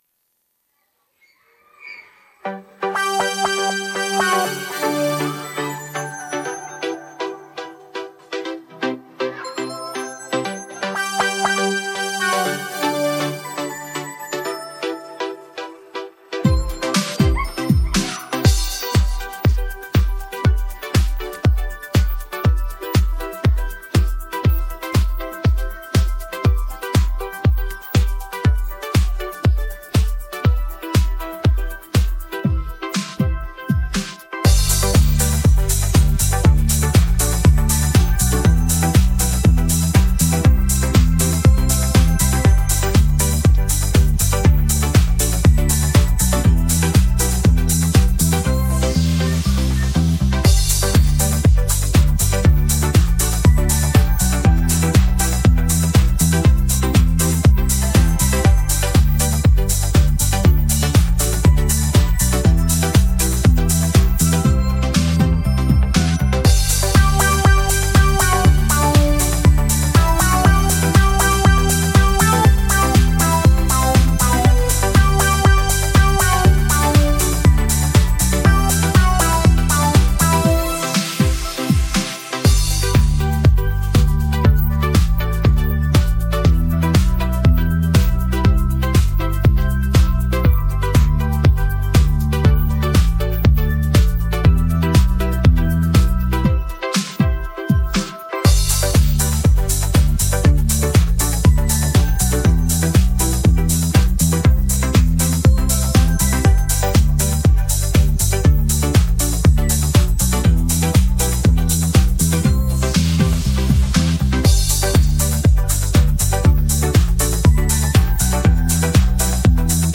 Скачать минус